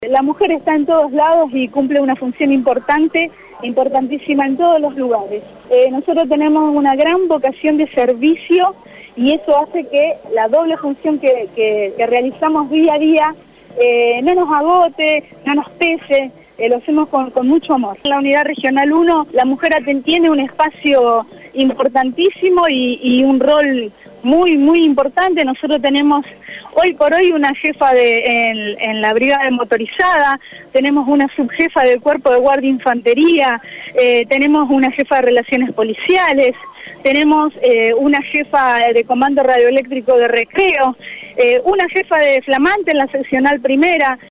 Desde la céntrica plaza San Martín de la capital provincial, la Policía de la Provincia agasajó a las mujeres de la fuerza en el Día Internacional de la Mujer.